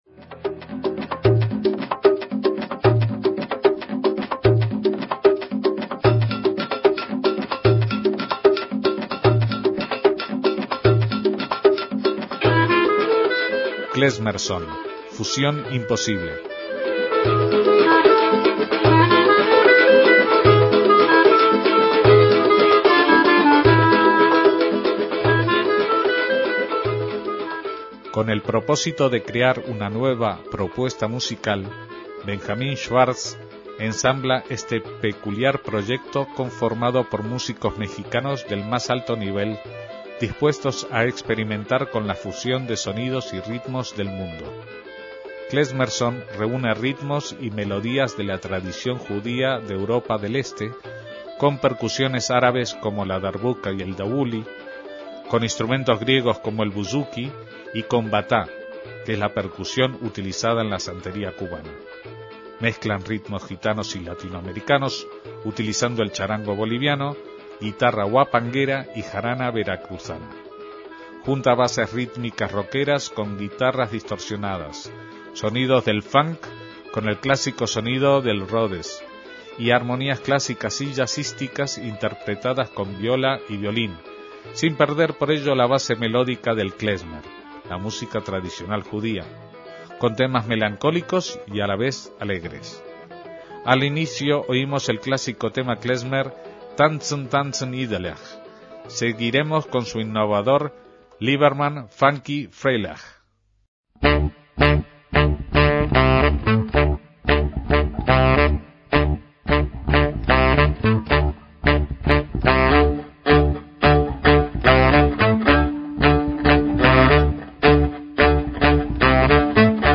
MÚSICA KLEZMER
guitarras y teclados
flauta y voz
jarana huasteca y percusiones